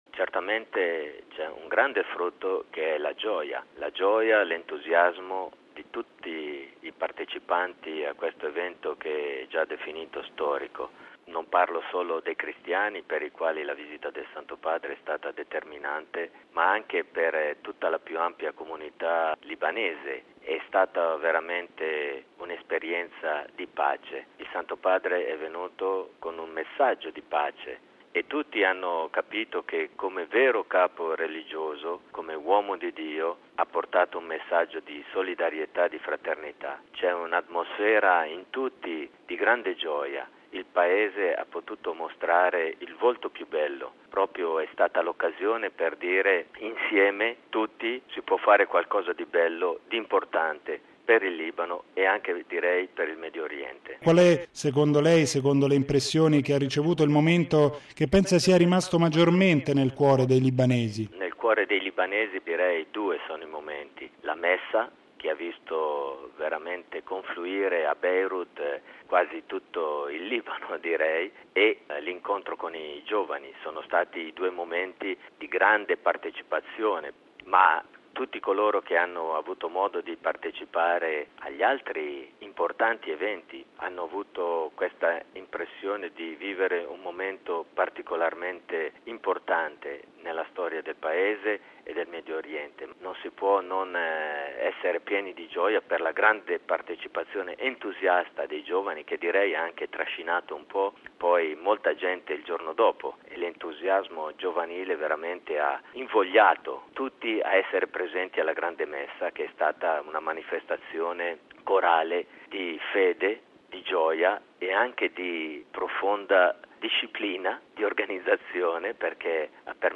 Radiogiornale del 21/09/2012 - Radio Vaticana